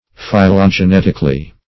phylogenetically - definition of phylogenetically - synonyms, pronunciation, spelling from Free Dictionary
-- Phy*lo*ge*net"ic*al*ly, adv.